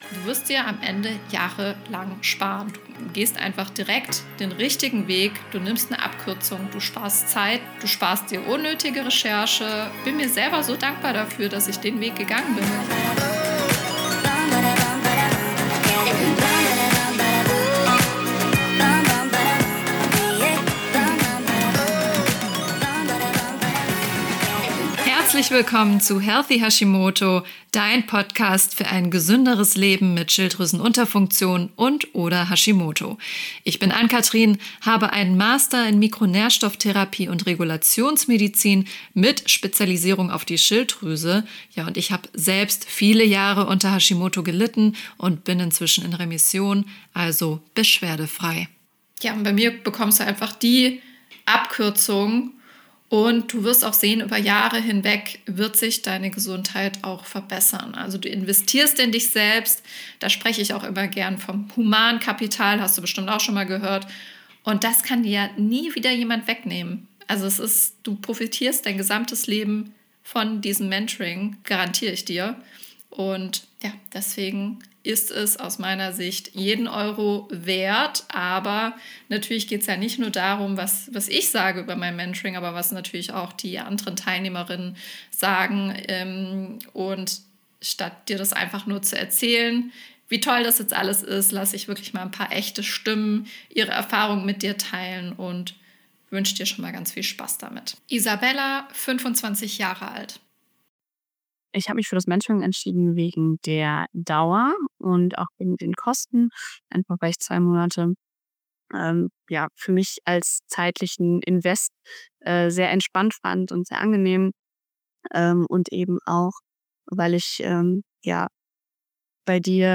Du überlegst, ob das Healthy Hashimoto Mentoring das Richtige für dich ist? In dieser Folge kommen nicht ich zu Wort – sondern die Frauen, die den Weg schon gegangen sind.